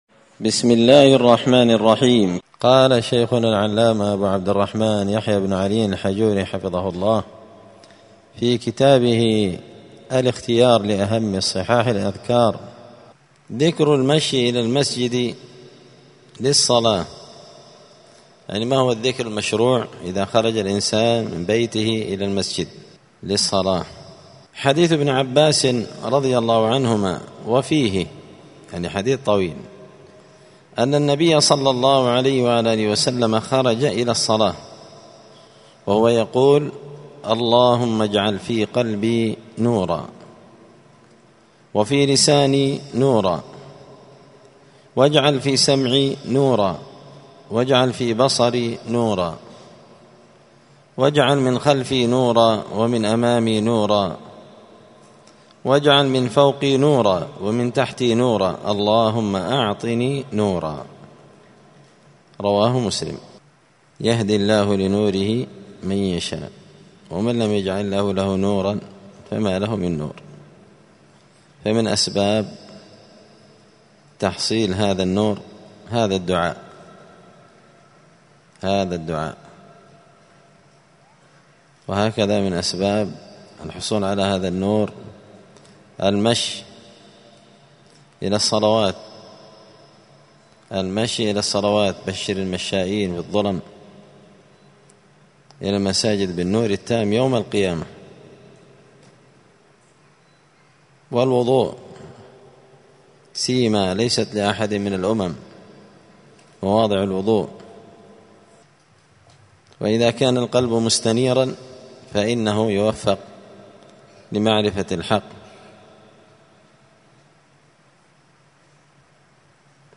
*{الدرس العشرون (20) ذكر المشي إلى المسجد للصلاة}*